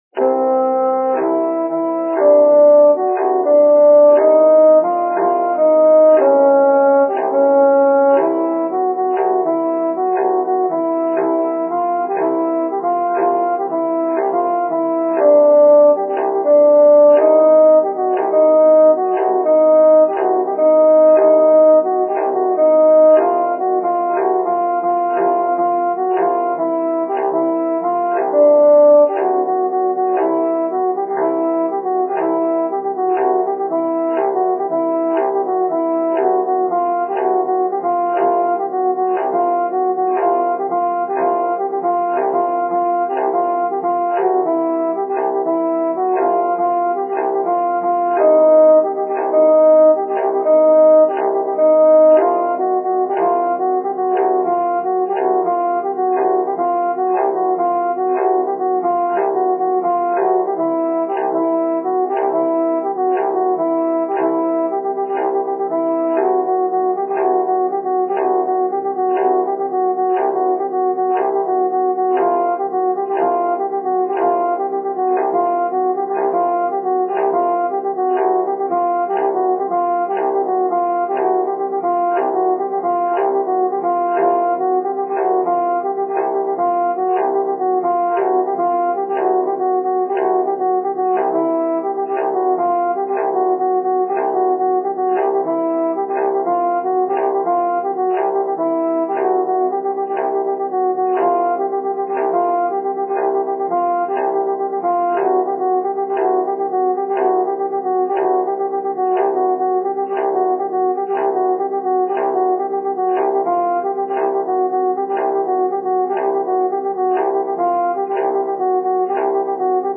En plus de leurs durées différentes, j'ai décidé d'associer une note à chaque chiffre : do pour la ronde (8), do# pour la blanche doublement pointée (7), ré pour la blanche pointée (6), ..., jusqu'à sol pour la croche (1).
Pour repérer les mesures, j'ai aussi ajouté à chacune un do ronde dans le grave.
Avec beaucoup d'indulgence, on pourrait ressentir une saturation phynale à la Ligeti, mais c'est quand même assez insupportable, non ?